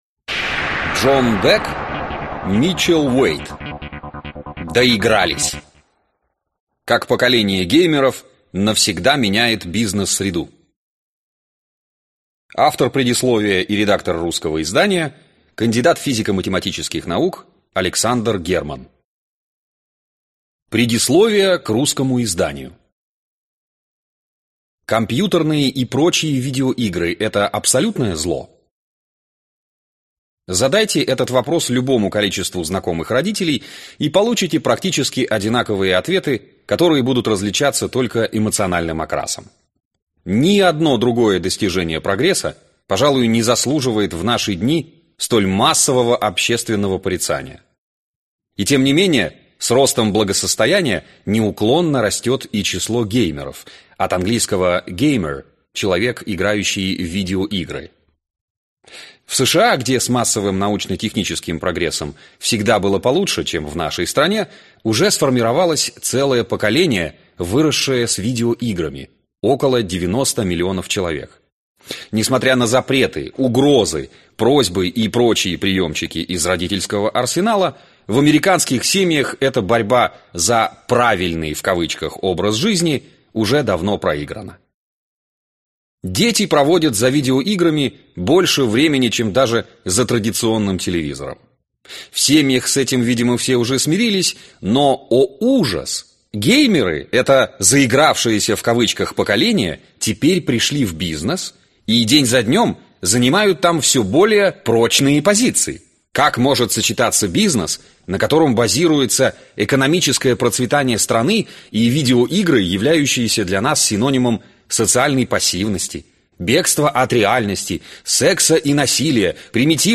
Аудиокнига Доигрались! Как поколение геймеров навсегда меняет бизнес-среду | Библиотека аудиокниг